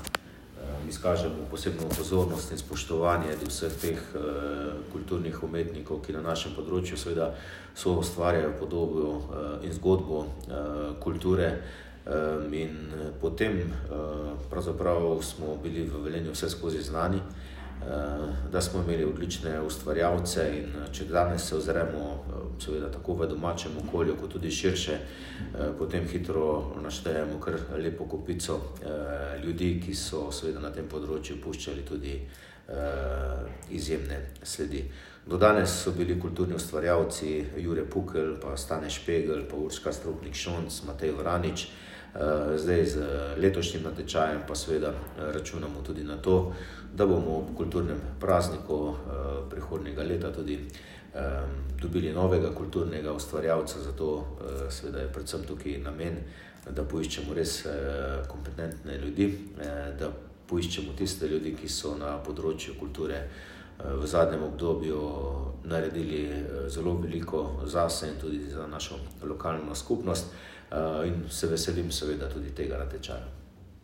Prilagamo županovo izjavo.
Izjava-zupana-kulturni-ustvarjalec-2025-2.m4a